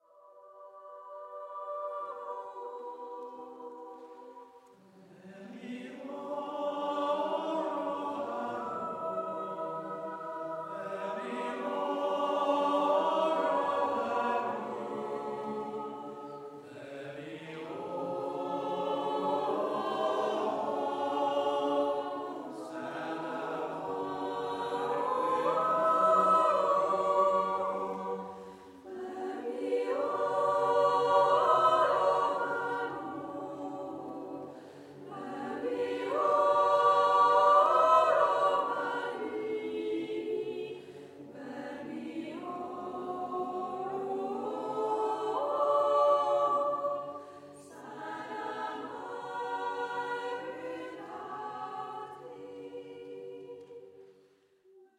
Unaccompanied anthems – often sung during the signing of the register: